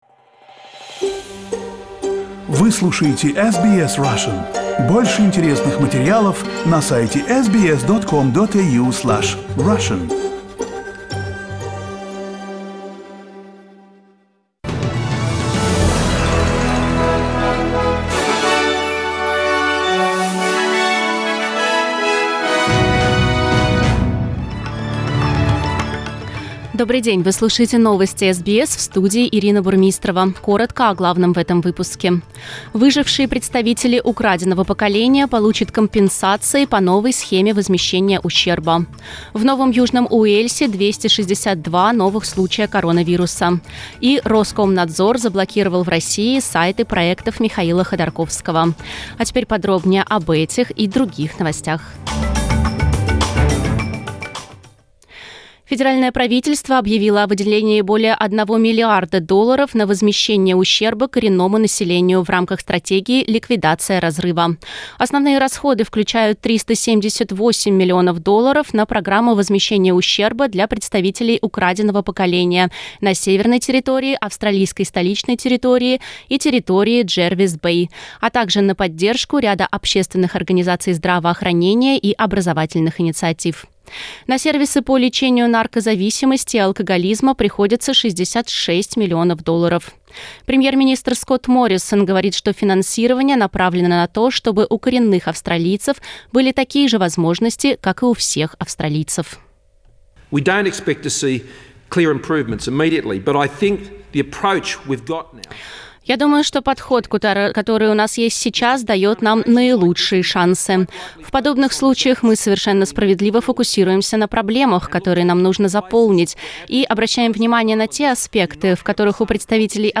Новости SBS на русском языке - 5.08